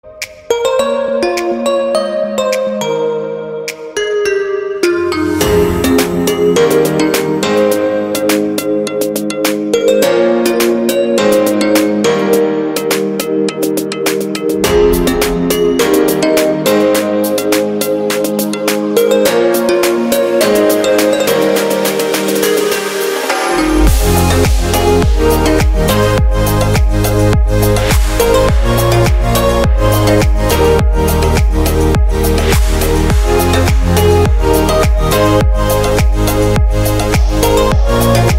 Kategorie Instrumentalny